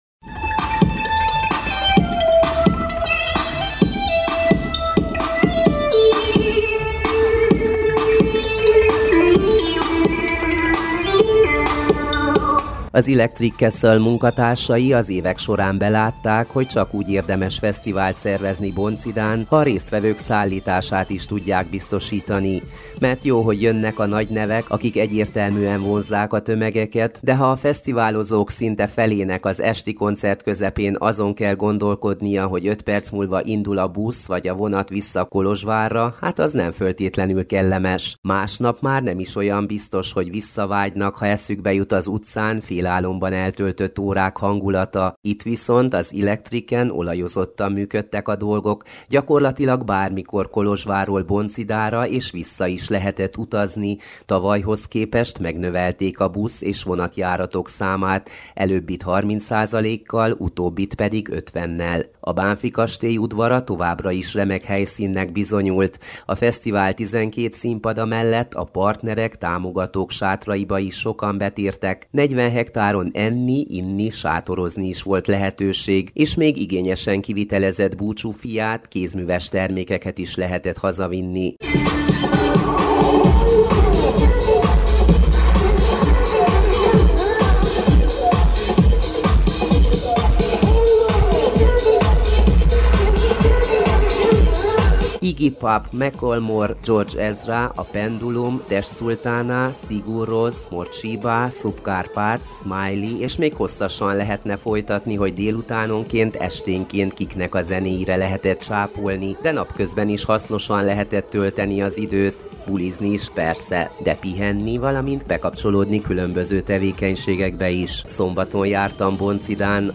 Hangok, hangulatok a bonchidai Electric Castle fesztiválról - Kolozsvári Rádió Románia